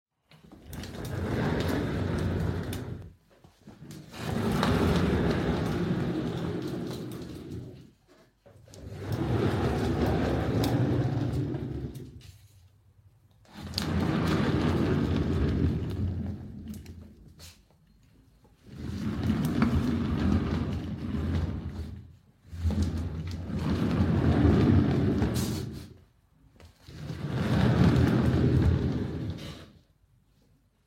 Tiếng Di chuyển Ghế văn phòng, ghế xoay, có bánh xe…
Tiếng Ghế điện kêu, tiếng Ngồi ghế điện… Tiếng Ghế bàn làm việc có bánh xe kêu
Thể loại: Tiếng đồ vật
Description: Âm thanh di chuyển ghế văn phòng, ghế xoay, ghế có bánh xe, tiếng lăn, kéo, trượt, nghiến, va chạm nhẹ trên sàn nhà. Hiệu ứng âm thanh này mô tả rõ tiếng bánh xe xoay tròn, ghế trượt nhẹ hoặc cọ xát, thường dùng trong chỉnh sửa video, phim, vlog hoặc game để tạo cảm giác chân thực trong không gian làm việc, văn phòng.
tieng-di-chuyen-ghe-van-phong-ghe-xoay-co-banh-xe-www_tiengdong_com.mp3